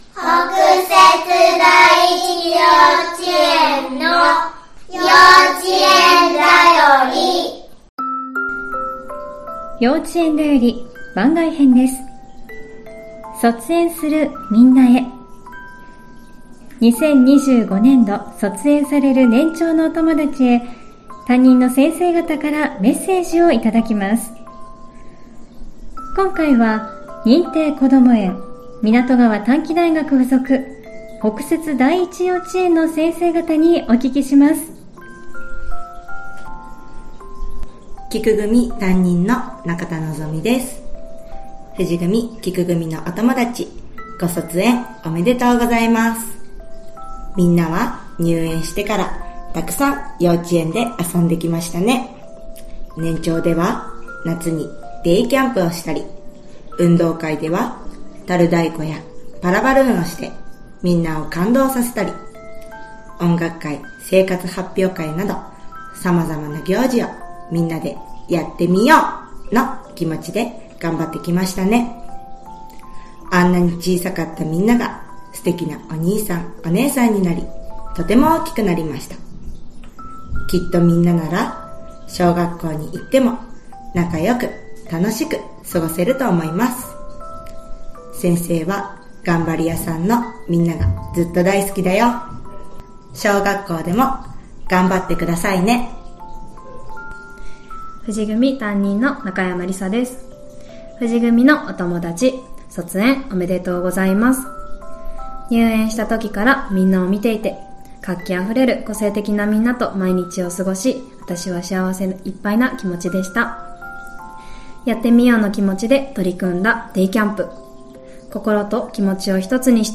2025年度卒園する年長さんへ、先生方からのメッセージをお届けします！